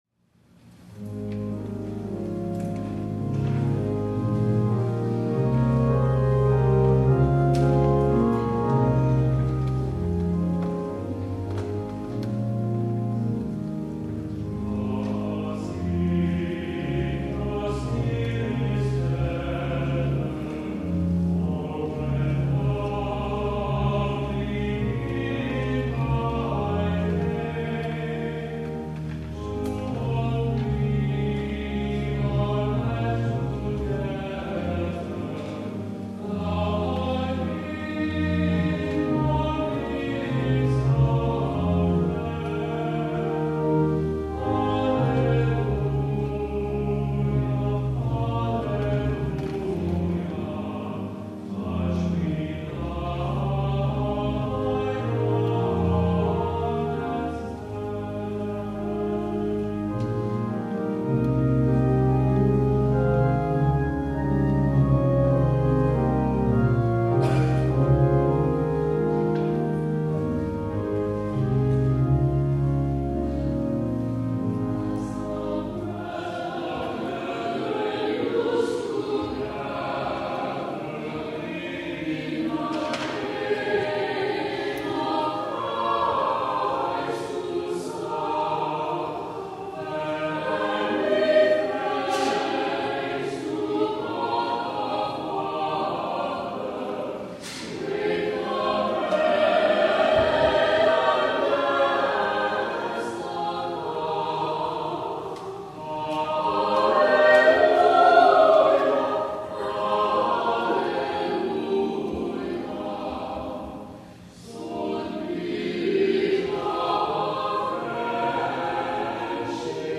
Pentecost
THE ANTHEM